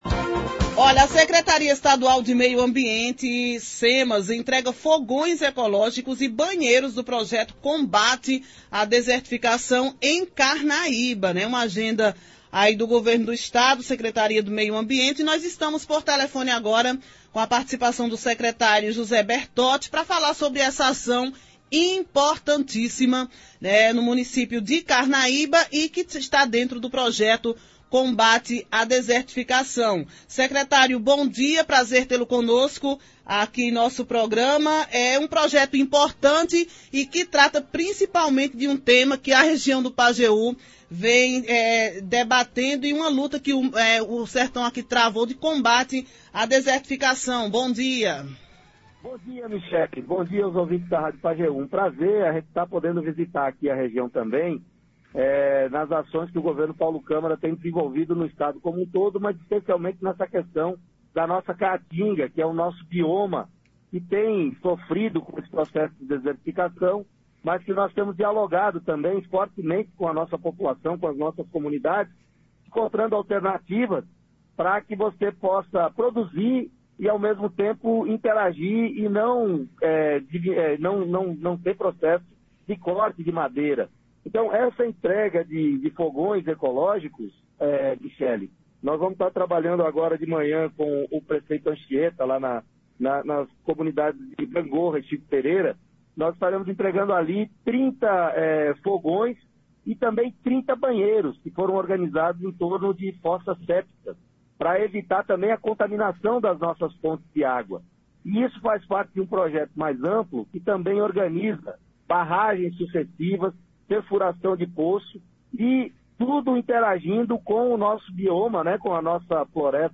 Ouça abaixo a íntegra da entrevista do secretário.